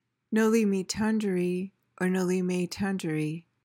PRONUNCIATION:
(NO-lee mee/may TAN-juh-ree)